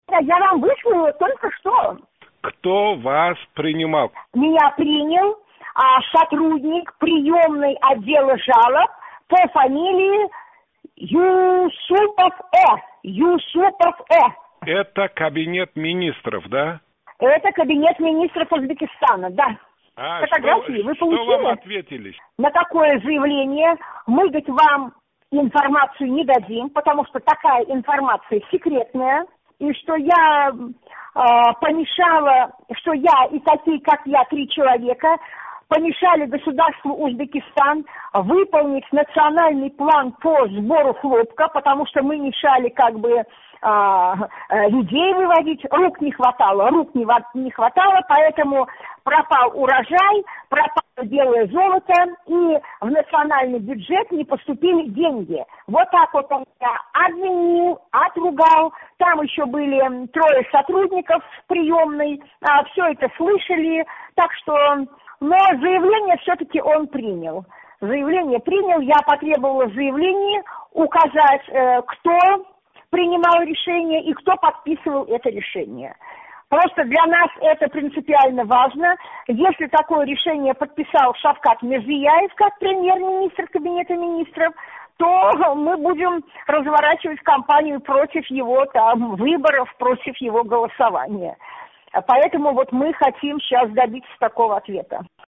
Ҳукумат биносидан эндигина чиққан суҳбатдошимиз бундай деди: